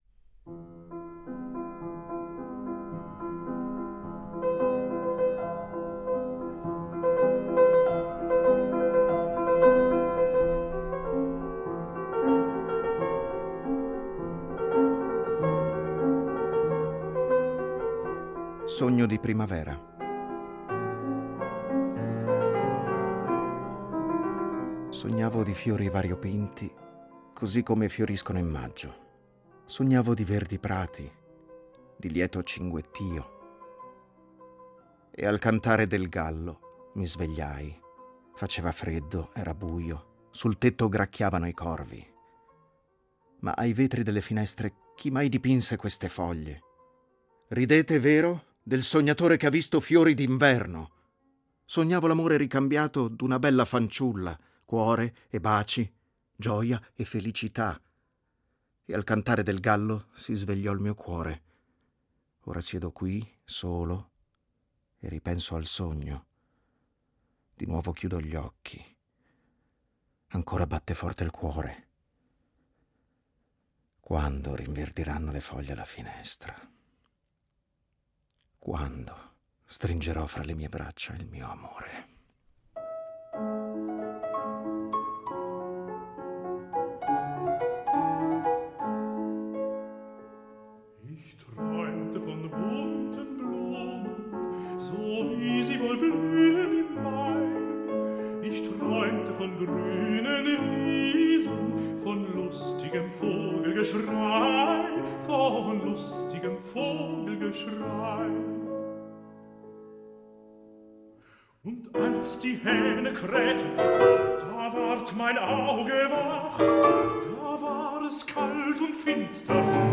Poesie interpretate
tenore
pianoforte